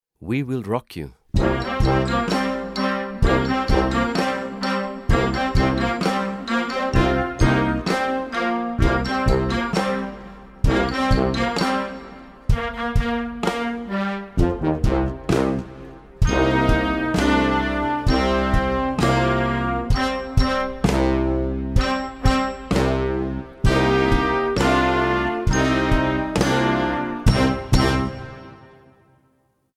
Gattung: Modernes Jugendwerk
Besetzung: Blasorchester